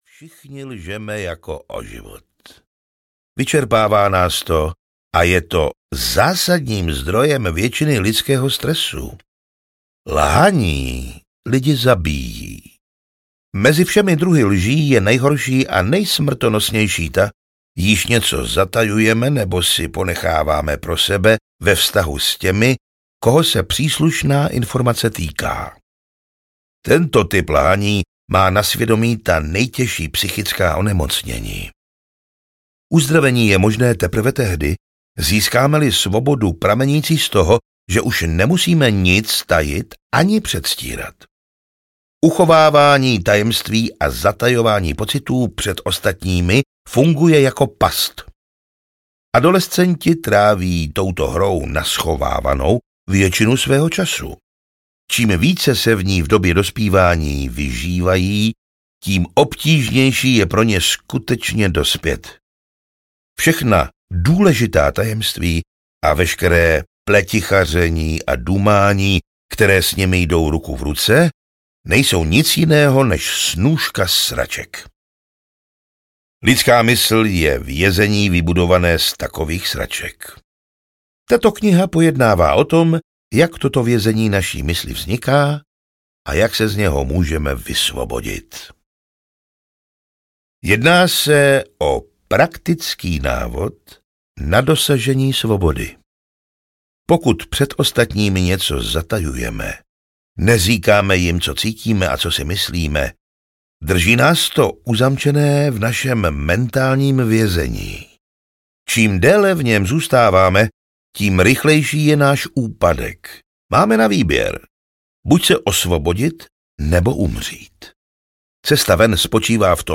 Radikální upřímnost audiokniha
Ukázka z knihy